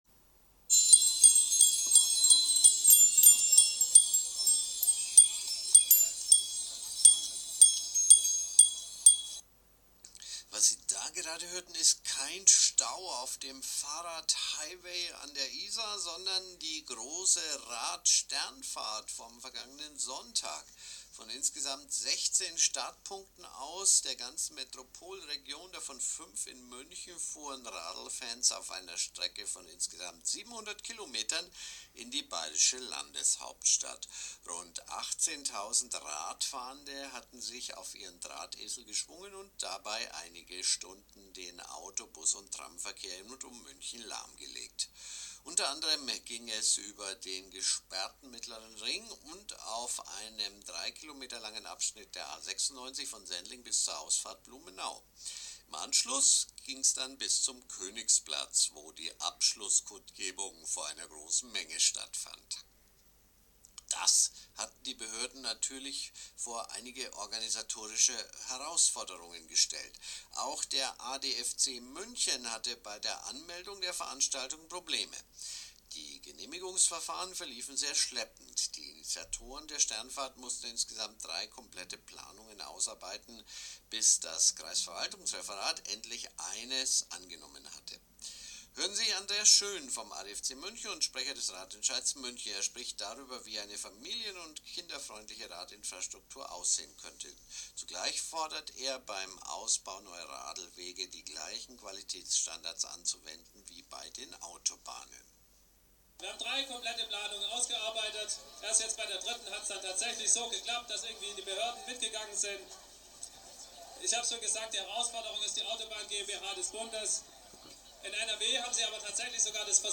Bei guter Stimmung und viel Musik bekräftigten die Redner:innen bei der Kundgebung auf dem Königsplatz die zentralen Forderungen nach umweltverträglicher Mobilität und mehr Radlkomfort. Ein Audio-Bericht